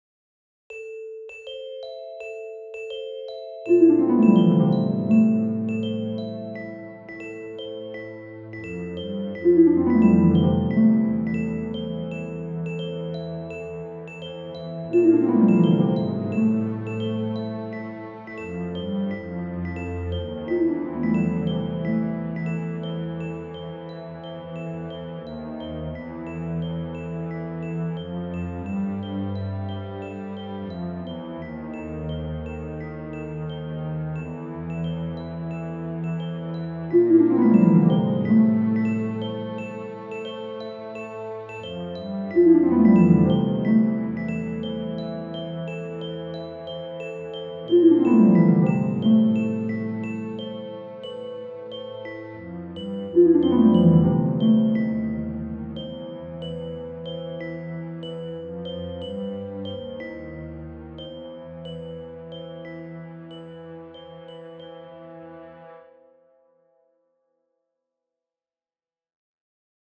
Die Musik sollte eine fantastische und mysteriöse Stimmung haben.
Somit hatten wir ein digitales Orchester schnell und einfach zur Hand.
Zum Ausprobieren wurden ein paar musikalische Konzeptionen erstellt.